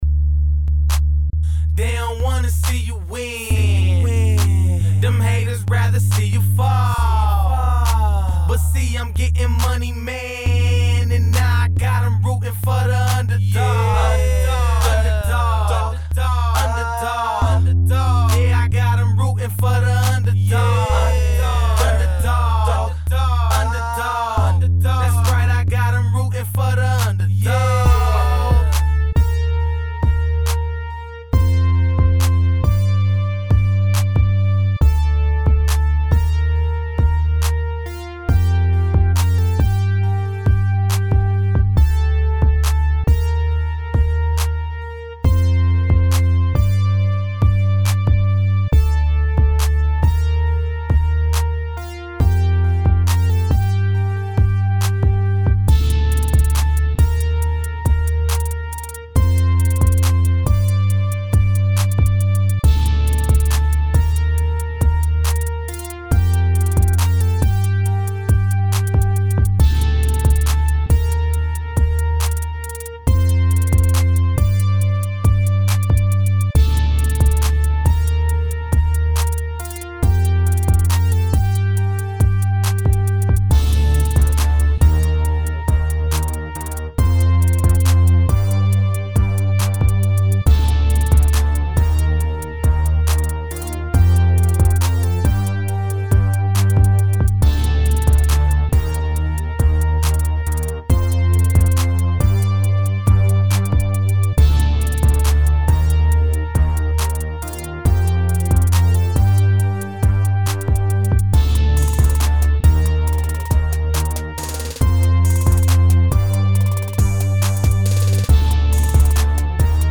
Hip-Hop/Rap
Electronic
West Coast Rap
Chicago House
Mood: Party Music